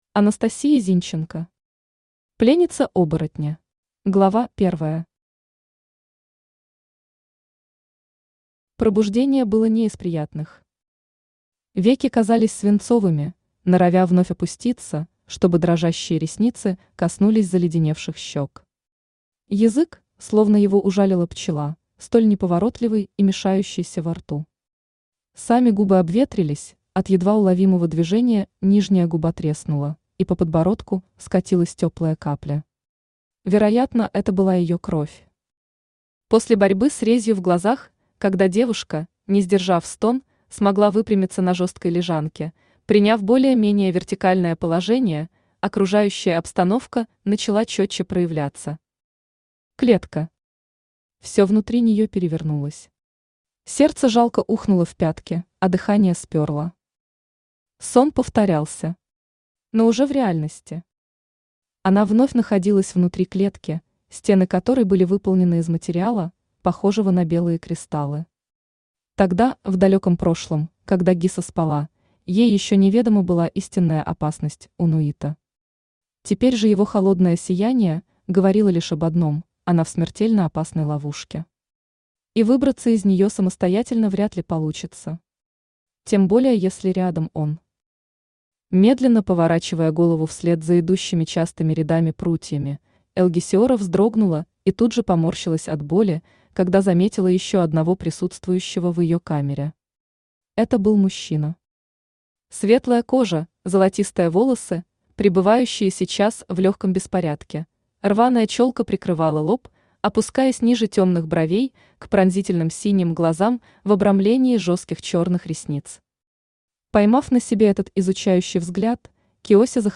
Аудиокнига Пленница оборотня | Библиотека аудиокниг
Aудиокнига Пленница оборотня Автор Анастасия Зинченко Читает аудиокнигу Авточтец ЛитРес.